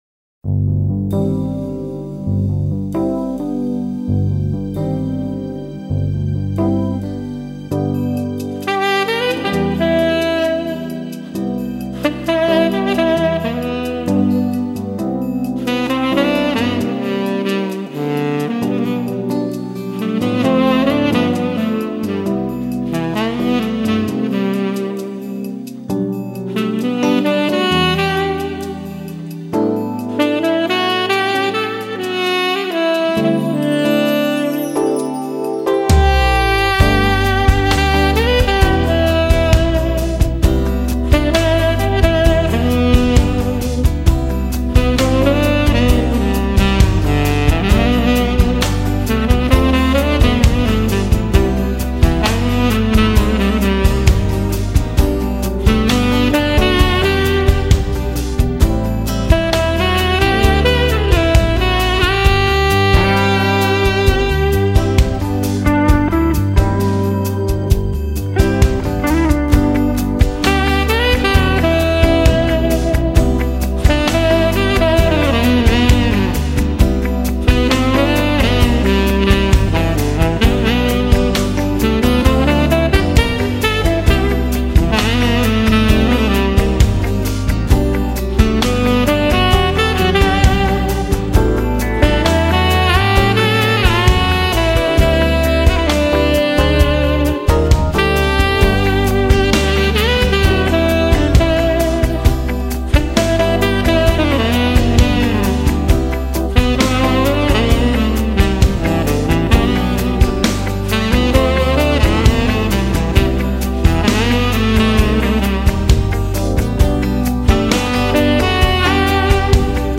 柔情薩克斯風
他..給我們貼心的撫慰 他..給我們萬般的沉醉 他..讓薩克斯風音化為一種酣甜的幸福氣味